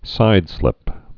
(sīdslĭp)